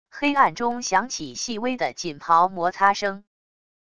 黑暗中响起细微的锦袍摩擦声wav音频